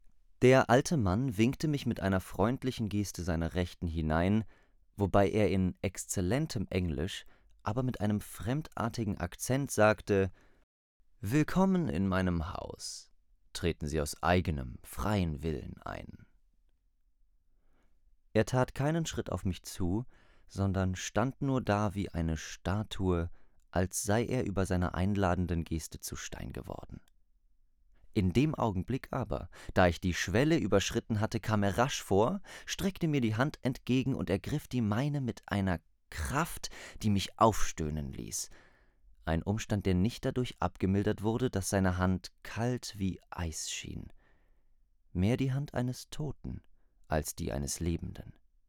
Hörbuch Dracula
Demo-Hoerbuch-Dracula.mp3